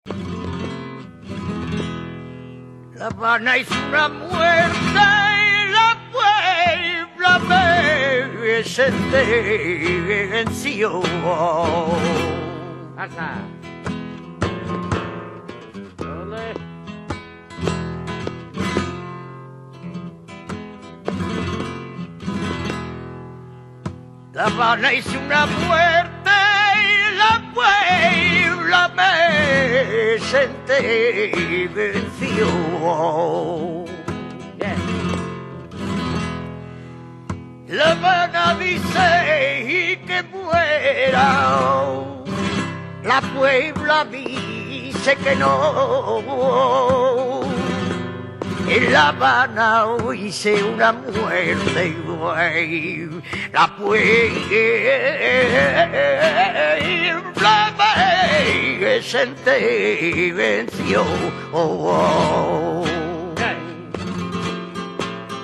Solea Petenera